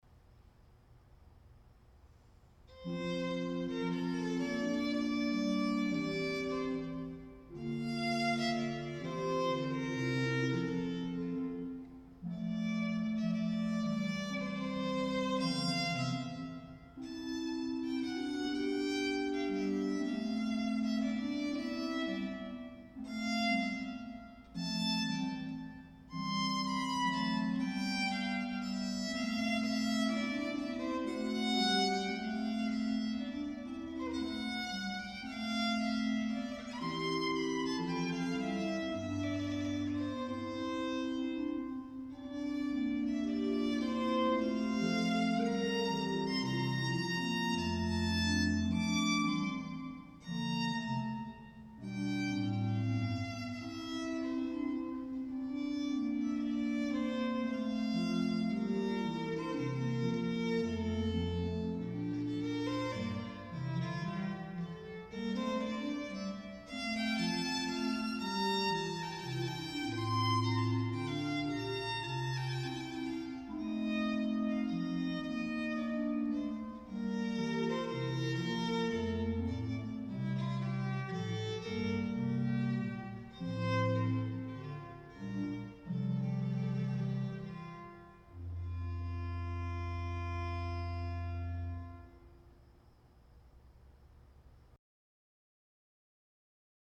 Music for Violin and Organ